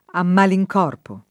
malincorpo , a [ a mmali j k 0 rpo ] locuz. avv.